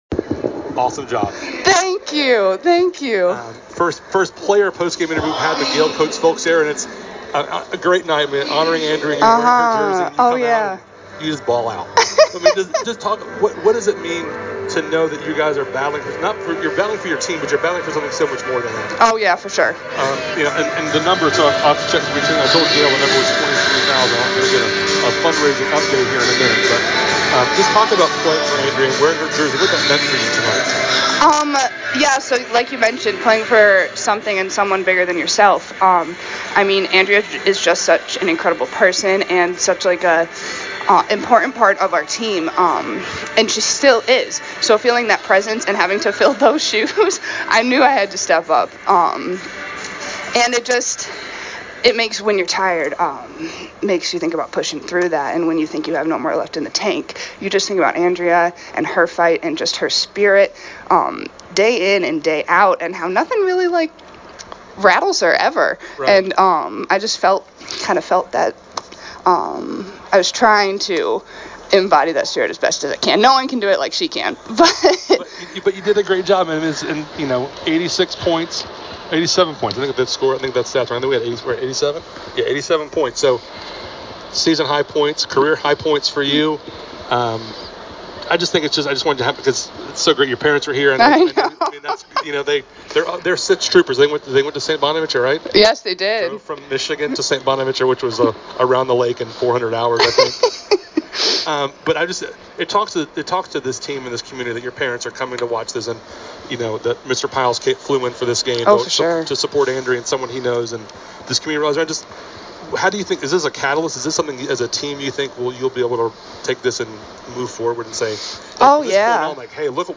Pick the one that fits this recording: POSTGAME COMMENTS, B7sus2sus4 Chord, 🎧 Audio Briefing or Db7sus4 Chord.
POSTGAME COMMENTS